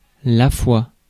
Ääntäminen
IPA: /fwa/